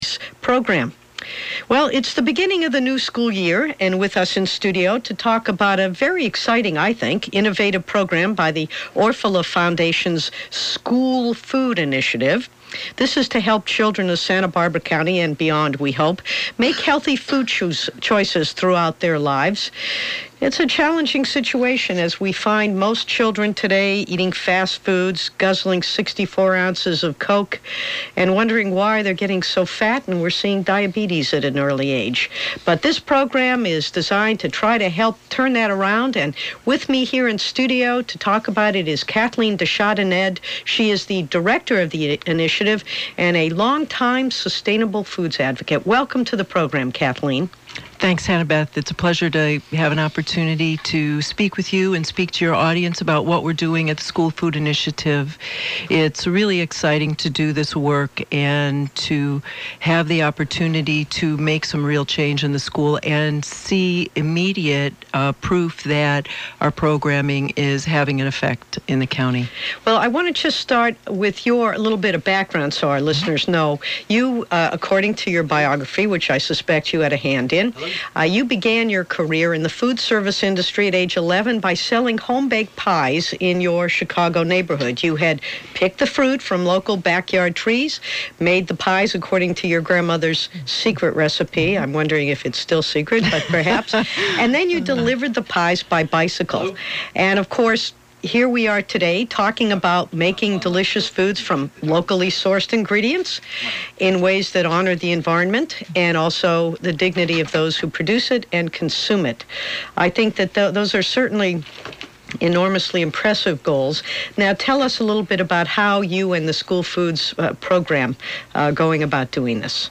A clip from the Hannah Beth Jackson radio show